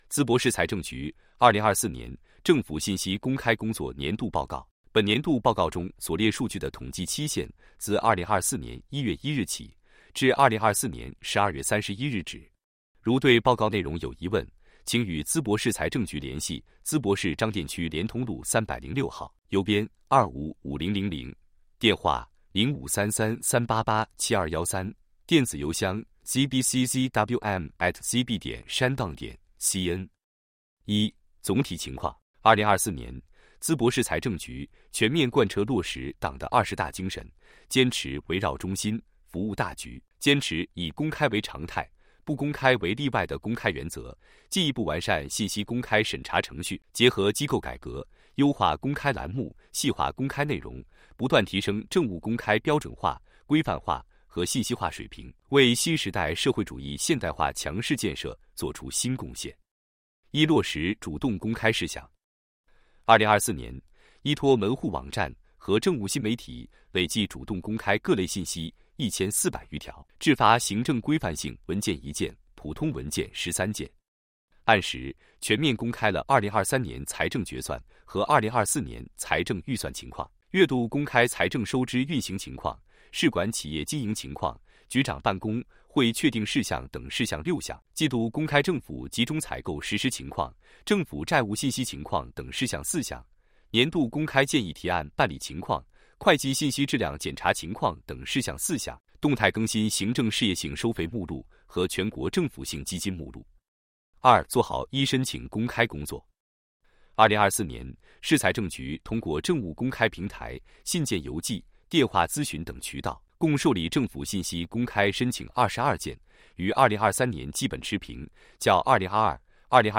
音频播报   |   图片解读